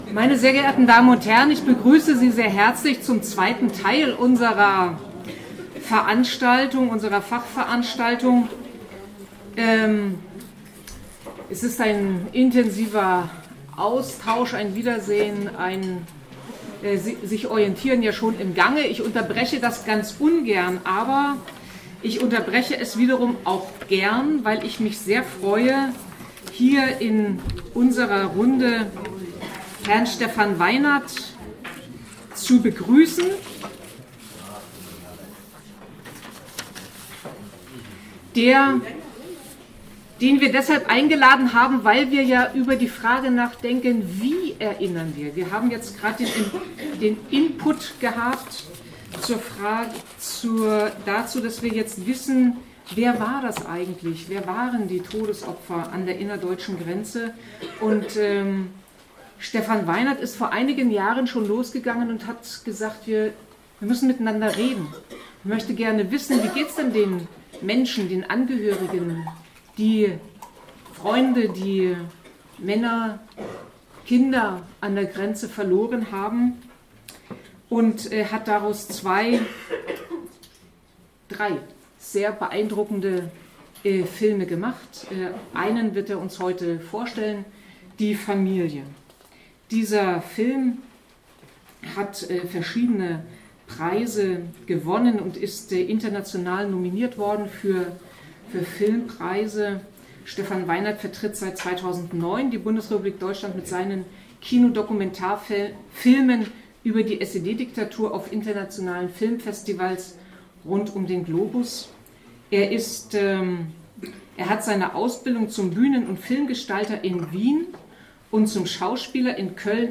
Dokumentation der Fachveranstaltung im Magdeburger Landtag 28.2.2018: „Die Todesopfer des DDR-Grenzregimes an der innerdeutschen Grenze 1949–1989 in Sachsen-Anhalt“
Vorstellung